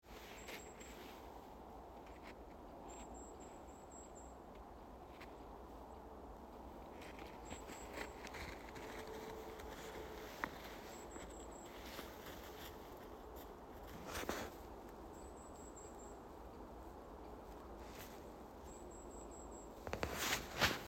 Birds -> Warblers ->
Goldcrest, Regulus regulus
StatusVoice, calls heard